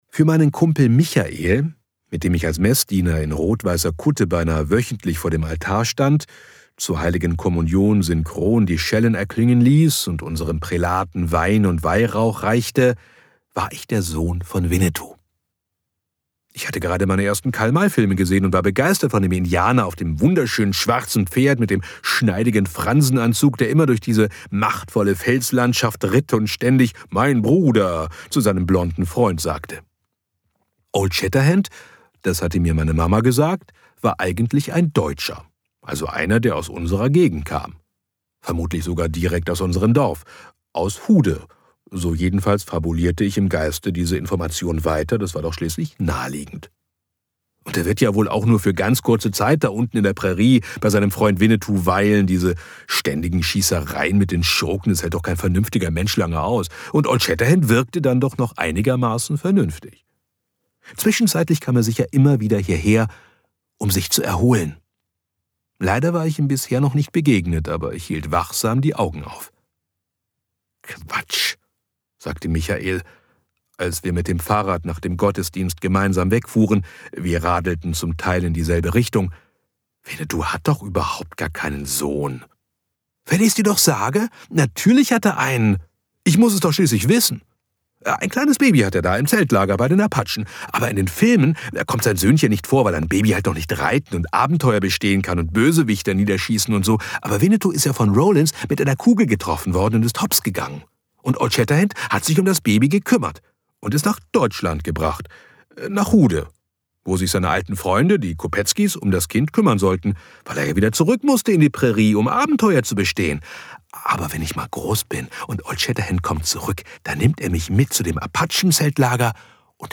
Diese bescheuerte Fremdheit in meiner Seele (Hörbuch)